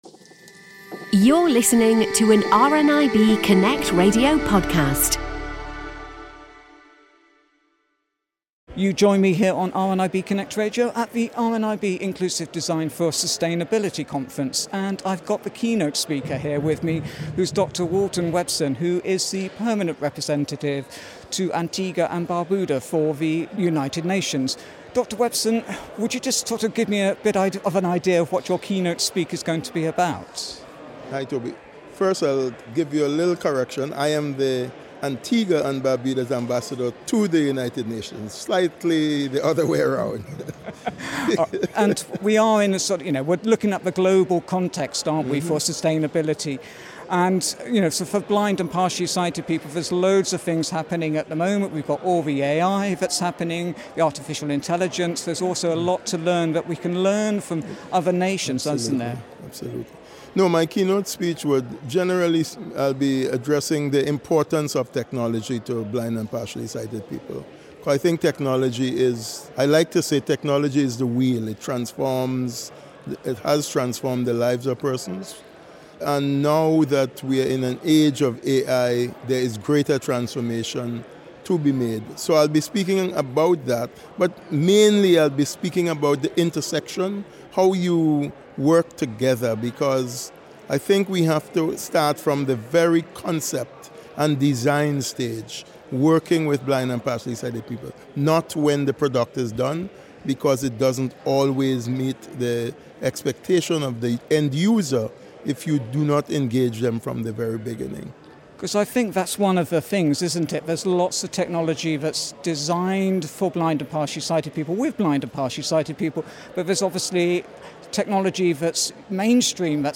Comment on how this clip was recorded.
At the RNIB Inclusive Design For Sustainability Conference in Glasgow